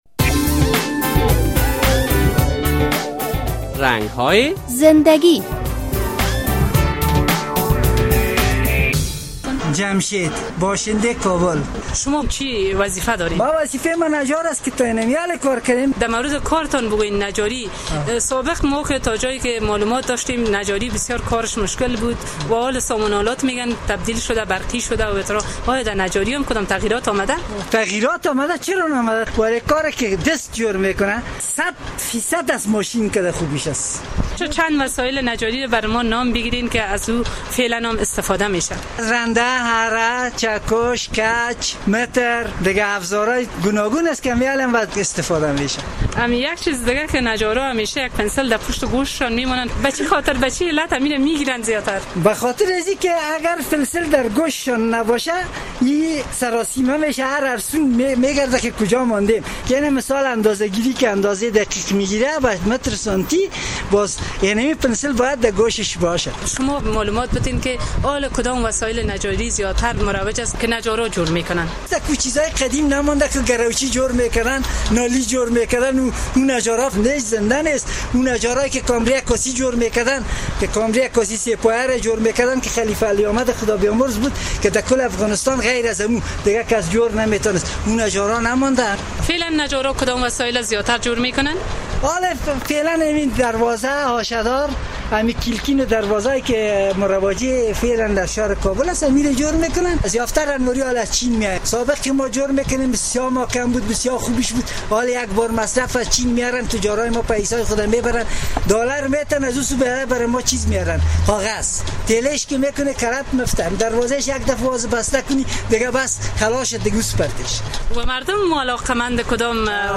در این برنامهء رنگ های زنده گی با یک تن از نجاران صحبت شده است.